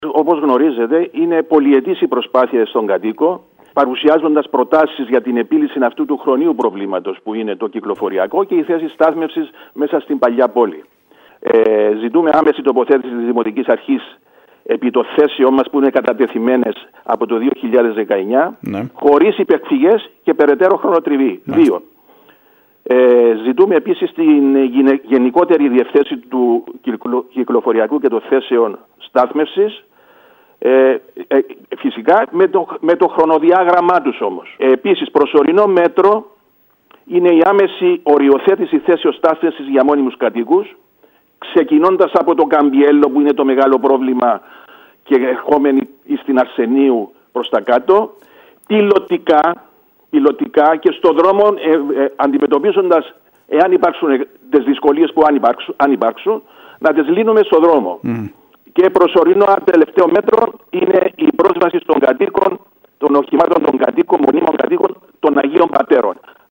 μιλώντας στην ΕΡΤ Κέρκυρας τόνισε